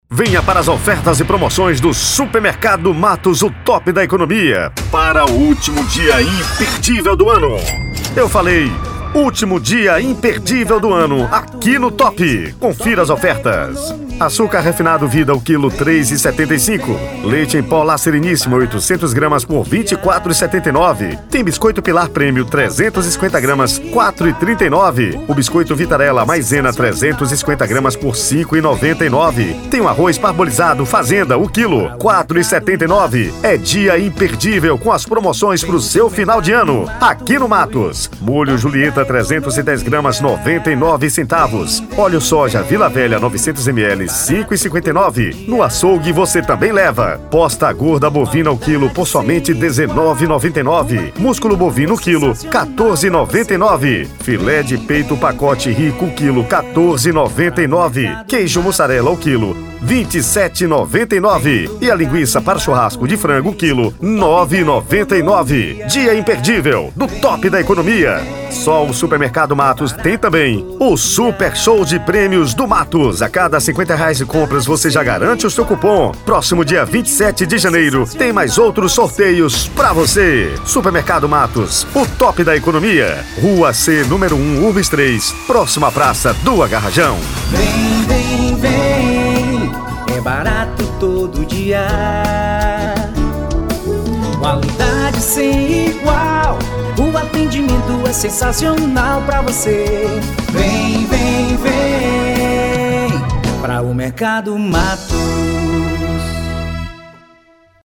Spot Comercial